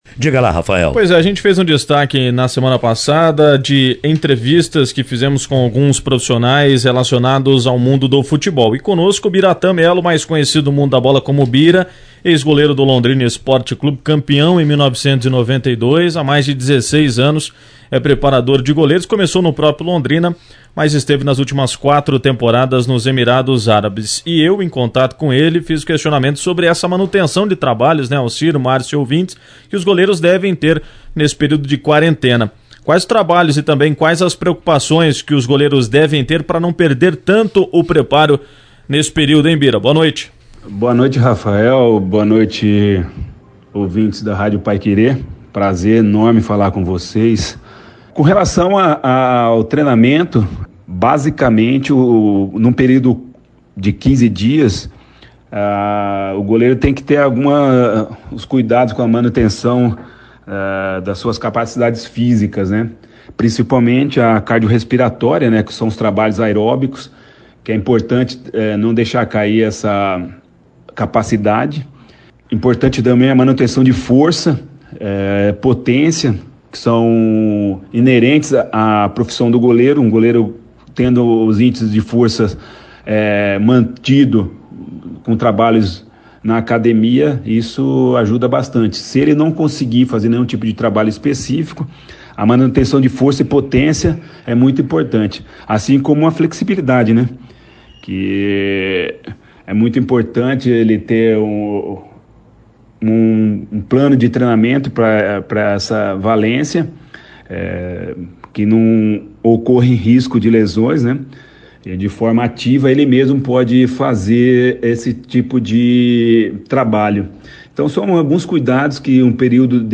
> Entrevistas > Ex-LEC, preparador dá dicas a goleiros sobre treinos durante paralisação